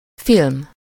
Ääntäminen
Synonyymit toile négatif court-métrage long-métrage Ääntäminen France Tuntematon aksentti: IPA: /film/ Haettu sana löytyi näillä lähdekielillä: ranska Käännös Ääninäyte 1. mozi 2. film Suku: m .